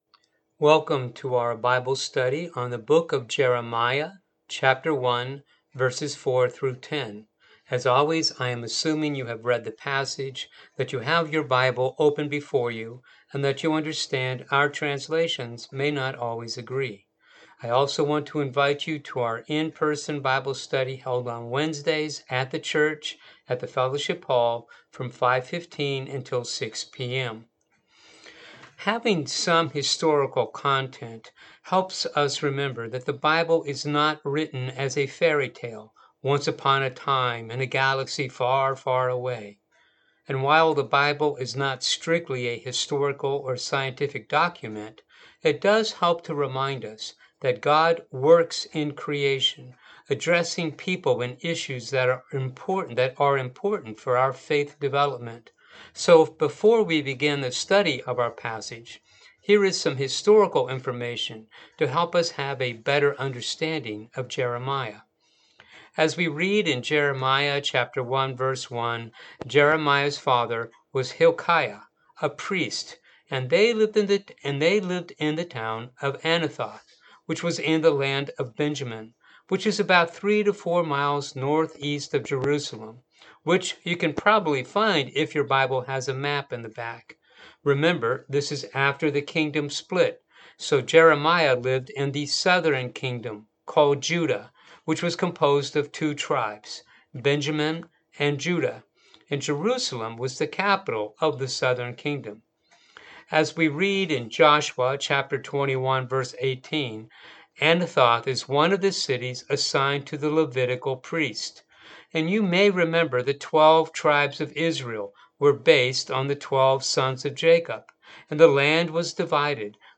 Bible Study for the August 28 Service